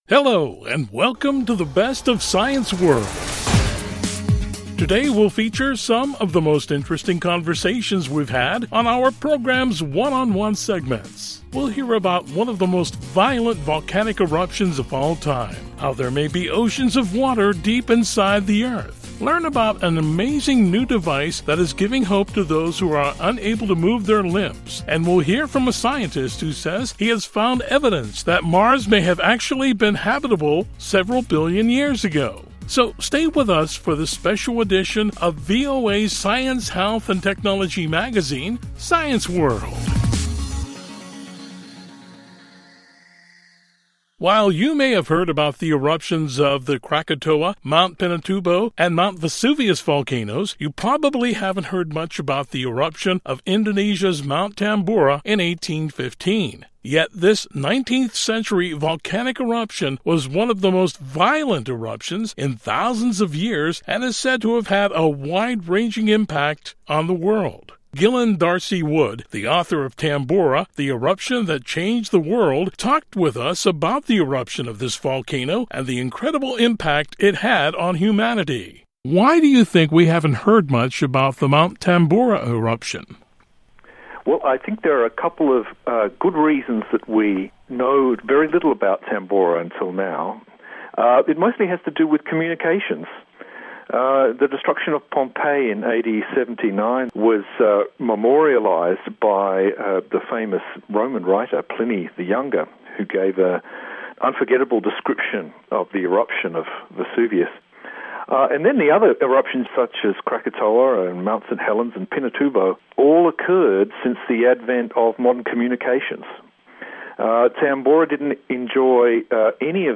Today we feature some of the most interesting conversations we've had on our program's ‘One on One’ segments. We'll hear about one of the most violent volcanic eruptions of all time. We talk with researchers who found evidence showing that there may be oceans of water deep inside the Earth. We’ll learn about an amazing new device that is giving hope to those who are unable to move their limbs. We’ll hear from a scientist who says he has found evidence that Mars may have actually been habitable several billion years ago.